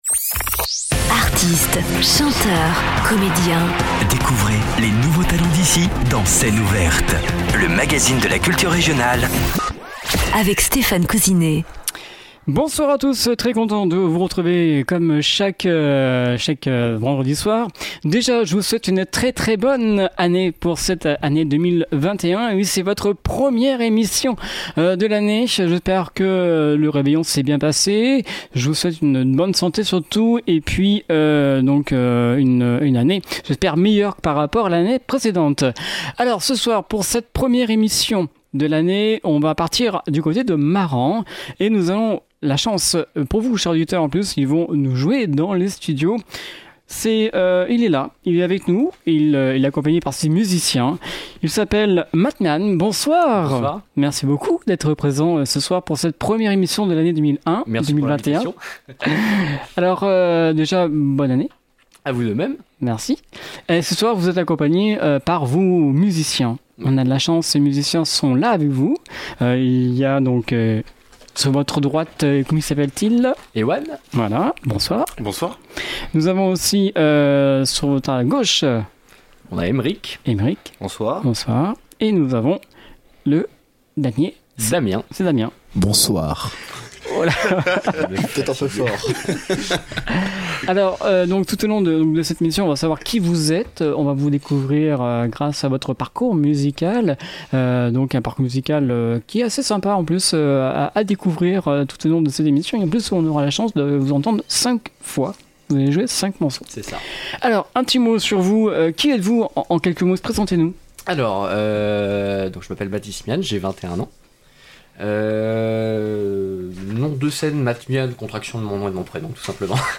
Scène ouverte